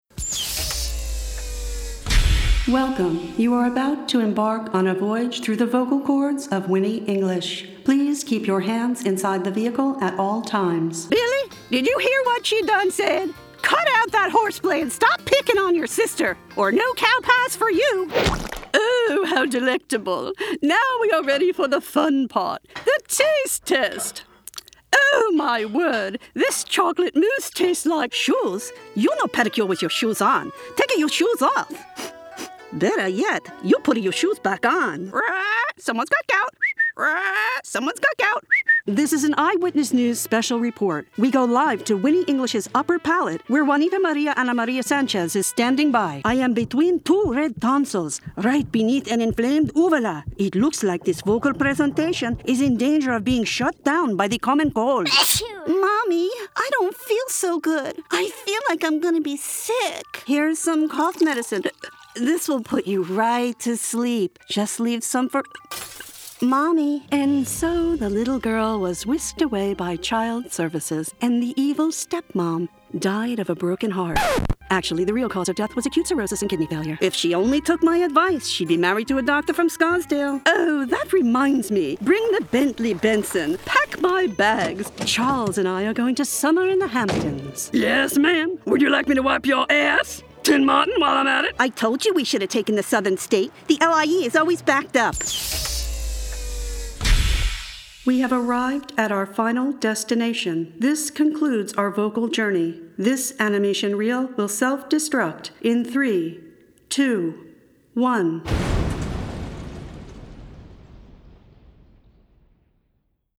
Adult, Mature Adult
Has Own Studio
I have a broadcast quality Home Studio:  ProTools 12, Neumann mic, sound booth, amplifier, Source Connect, phone patch, FTP and any delivery method.
southern us | natural
standard us | natural
ANIMATION 🎬
smooth/sophisticated
warm/friendly